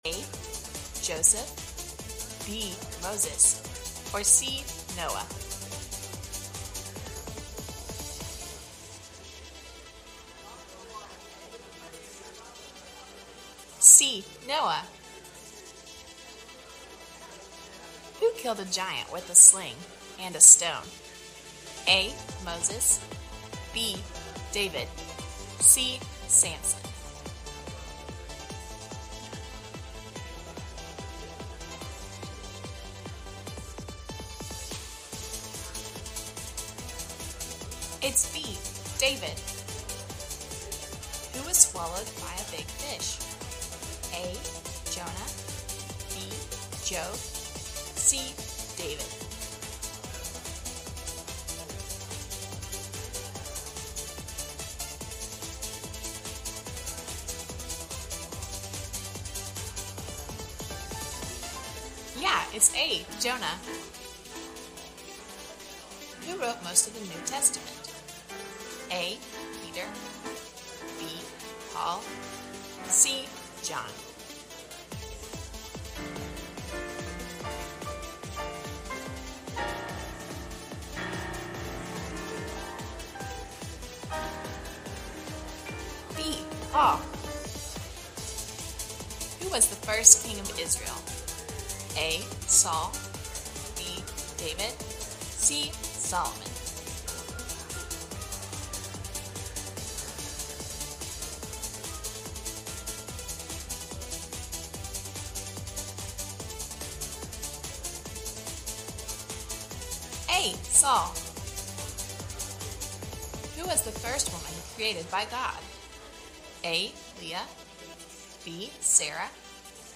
Mark 15:9-21 Service Type: Sunday Morning « Dealing With Depravity